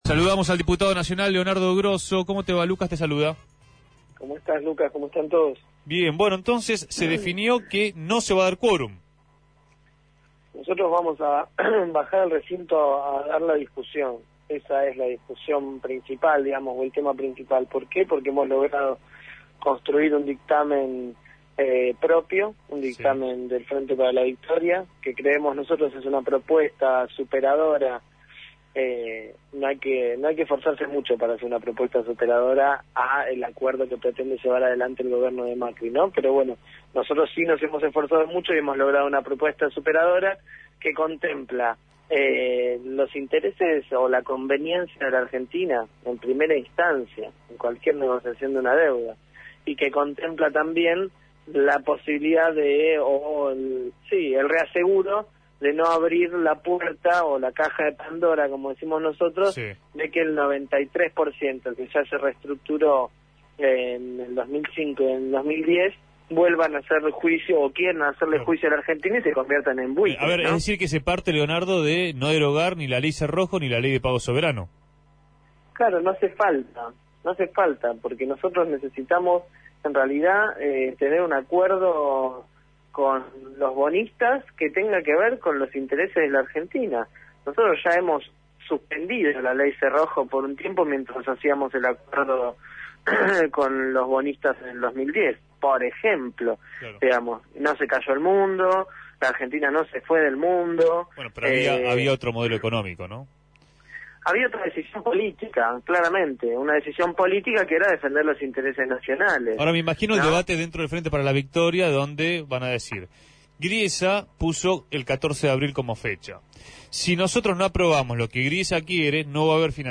Leonardo Grosso, diputado nacional por el Frente Para la Victoria y referente del Movimiento Evita, fue entrevistado en Punto de Partida respecto a la sesión que se dará el martes 15 en la Cámara Baja y tendrá como eje principal el proyecto de acuerdo con los fondos buitre.